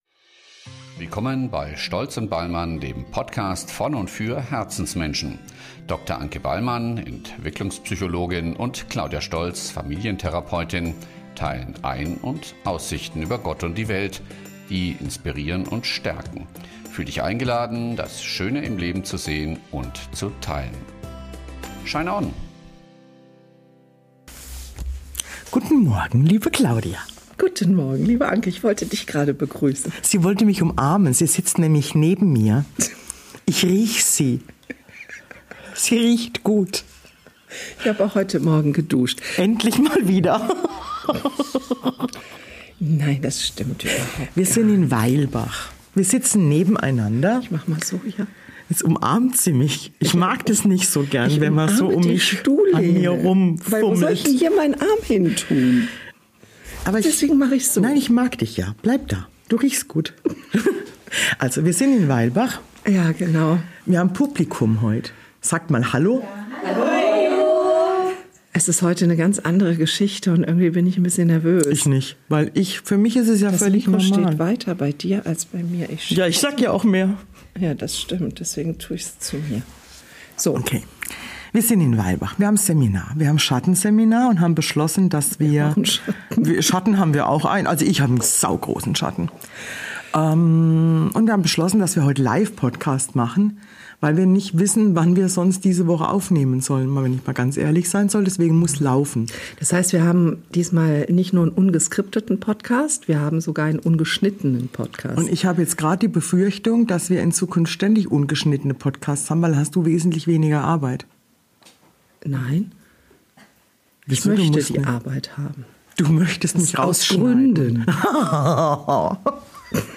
Heute sind wir live aus dem Odenwald zu hören.